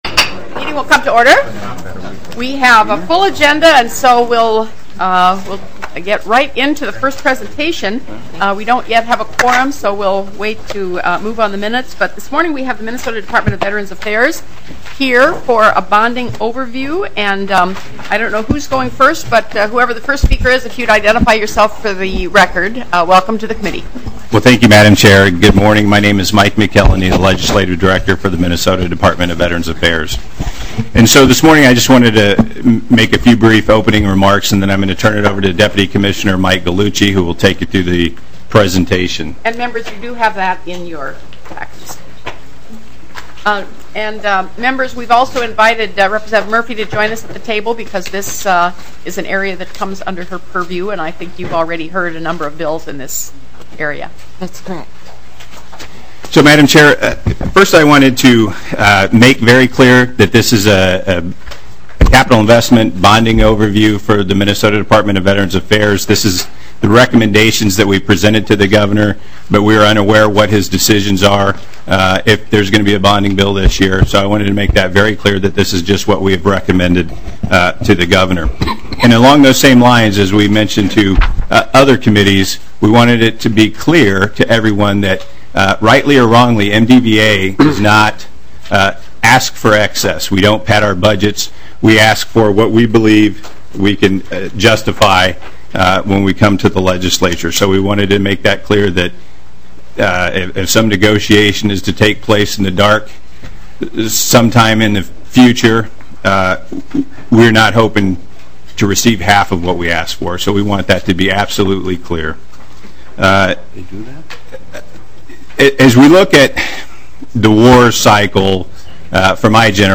House Taxes Committee Meeting